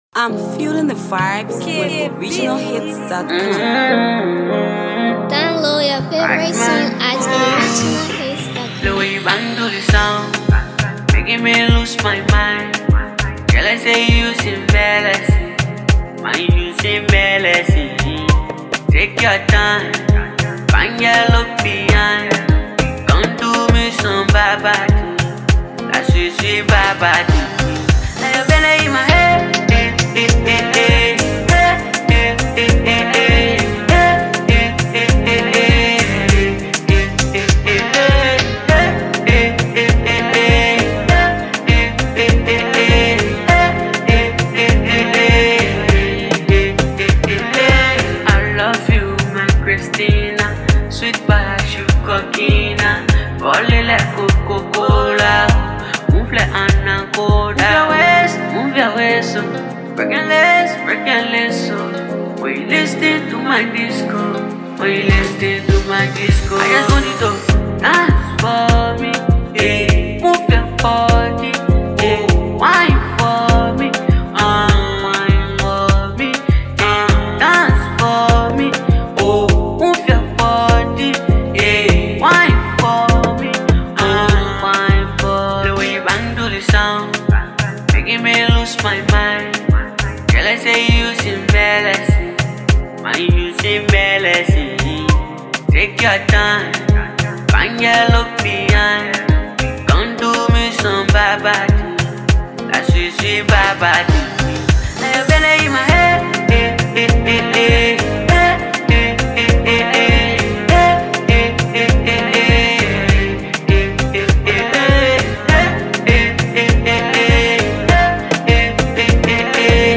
Liberian singer and talented vocalist
studio track
This song is a banger